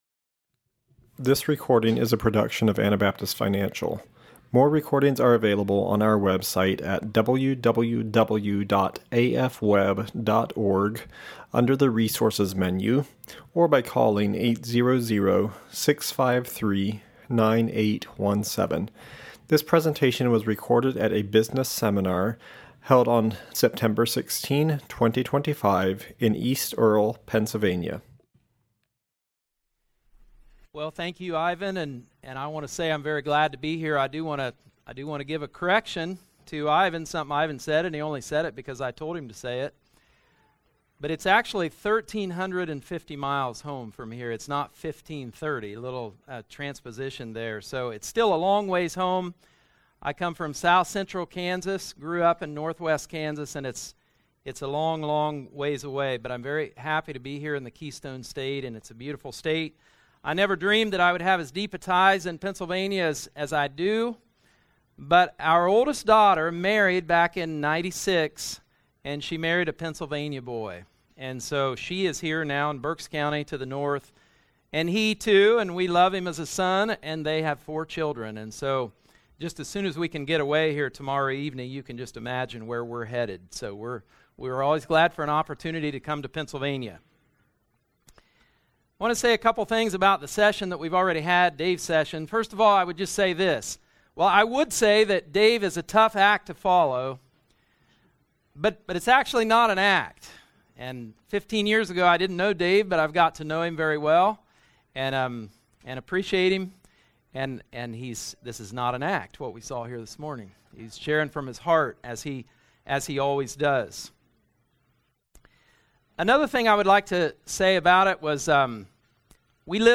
Pennsylvania Business Seminar 2025